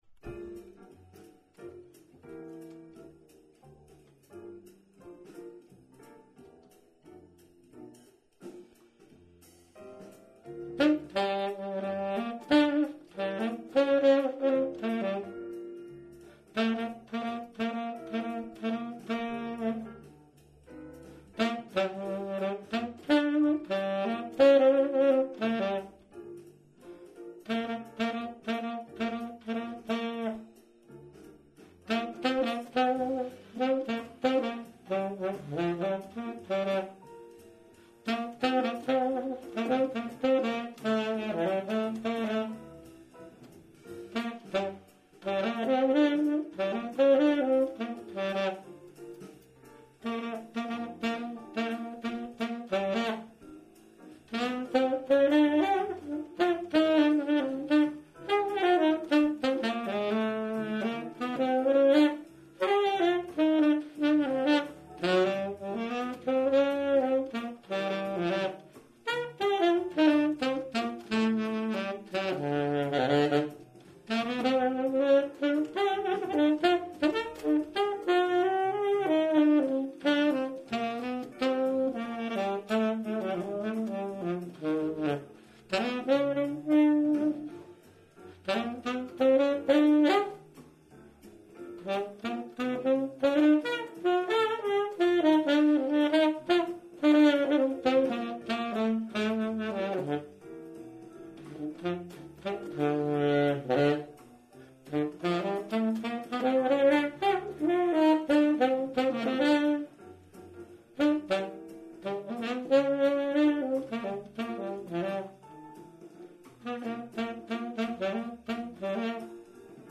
TENOR SAX AUDIO RECORDINGS !
All the tenor tunes recorded in Toulouse (France)